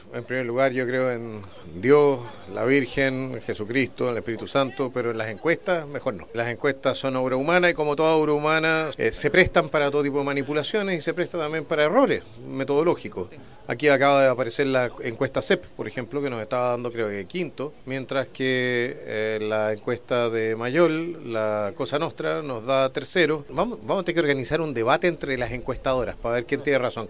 Declaraciones que hizo durante su paso por Valdivia, donde viajó en una embarcación con un grupo de adherentes para recorrer el río Calle-Calle.